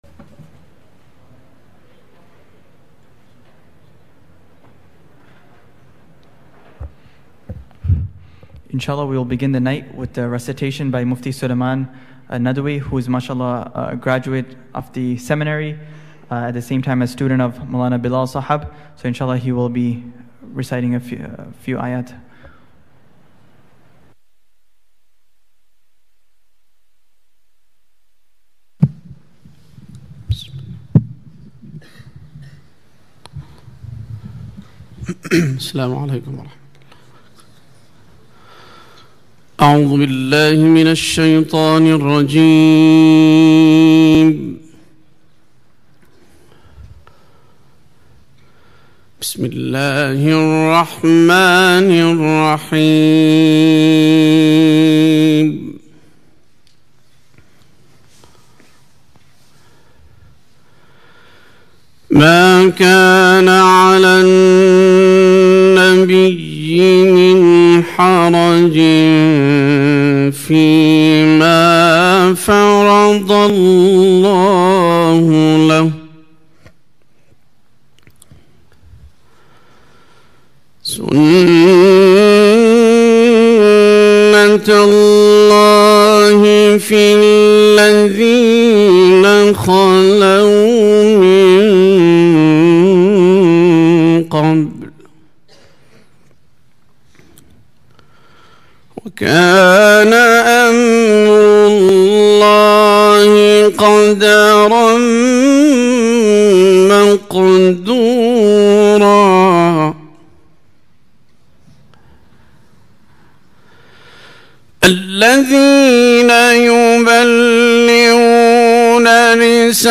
Urdu Lecture